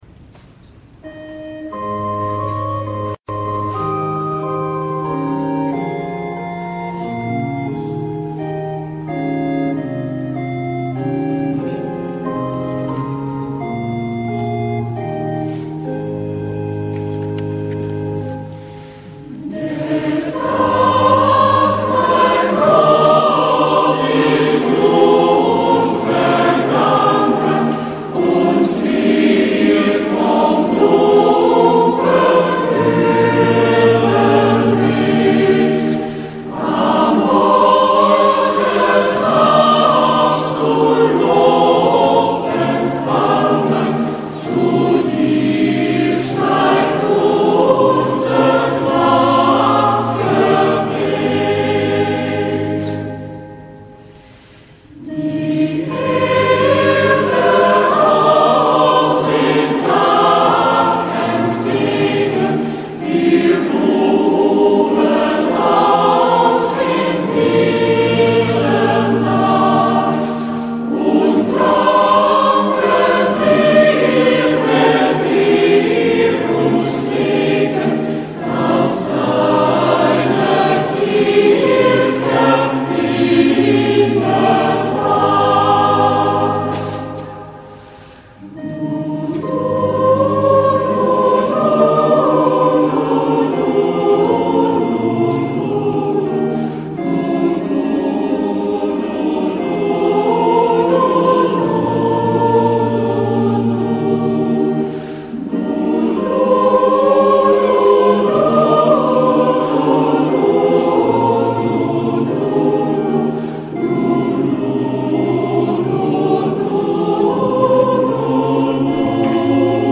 Muziek concert 2009 en missen: